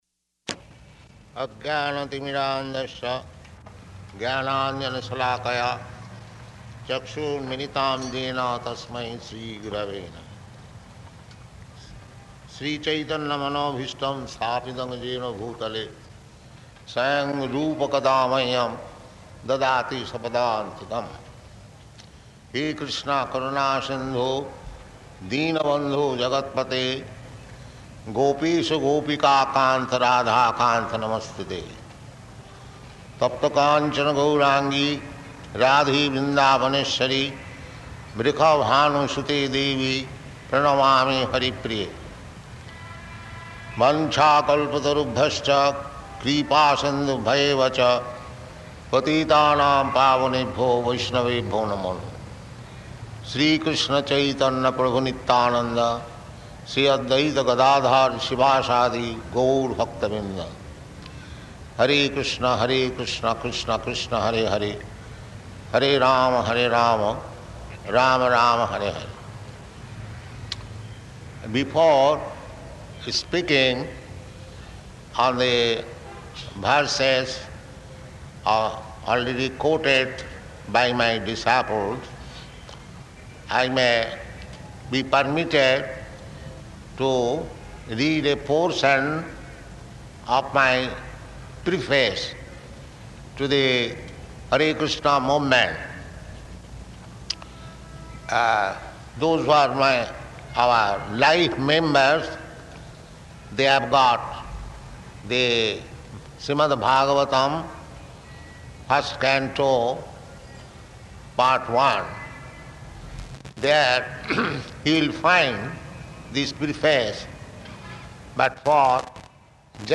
Śrīmad-Bhāgavatam Preface, Pandal Lecture
Location: Bombay
[chants maṅgalācaraṇa prayers]